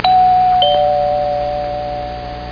DOORBEL2.mp3